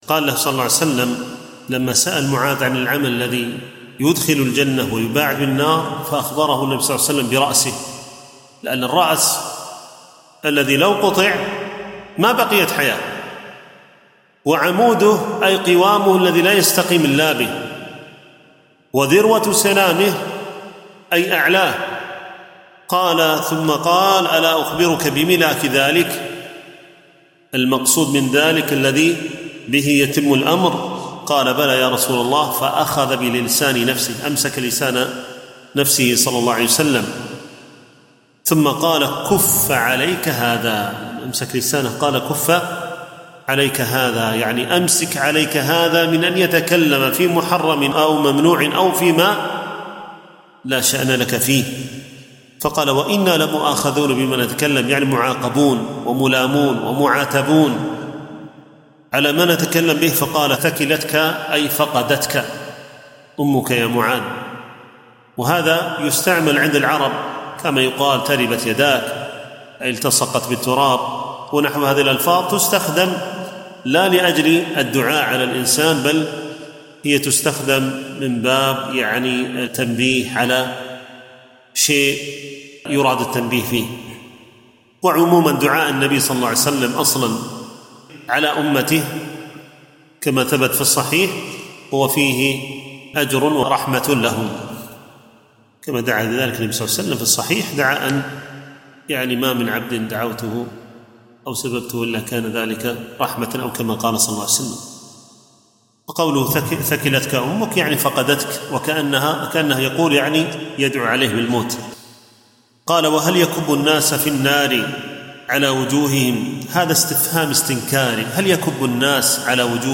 التنسيق: MP3 Mono 48kHz 101Kbps (VBR)